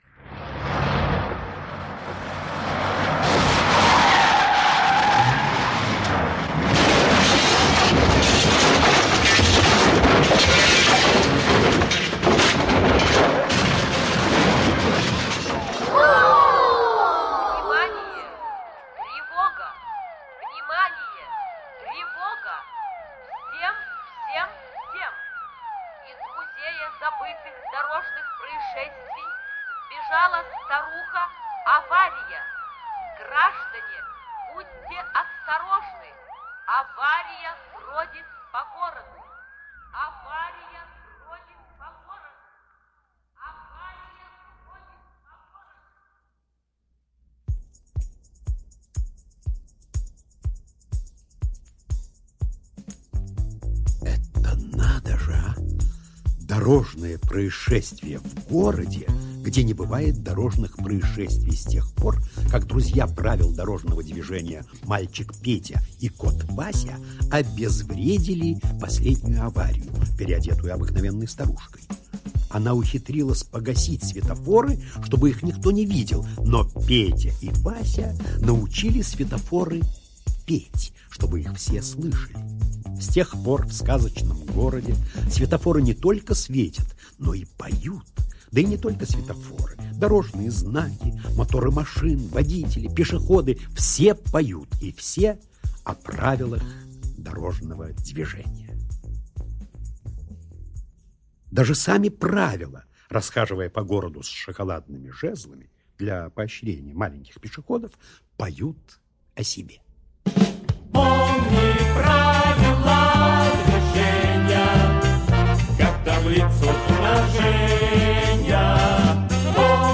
Новые приключения в городе поющих светофоров - аудиосказка Азова М.Я. Сказка о том, что все должны знать правила дорожного движения.